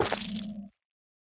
Minecraft Version Minecraft Version 1.21.5 Latest Release | Latest Snapshot 1.21.5 / assets / minecraft / sounds / block / sculk_sensor / place4.ogg Compare With Compare With Latest Release | Latest Snapshot